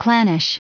Prononciation du mot clannish en anglais (fichier audio)
Prononciation du mot : clannish